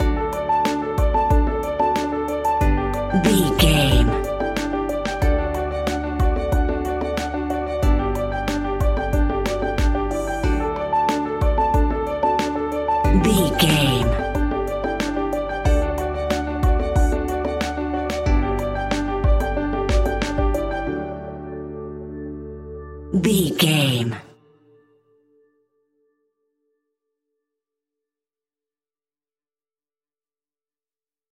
Ionian/Major
D
pop rock
indie pop
energetic
upbeat
groovy
guitars
bass
drums
piano
organ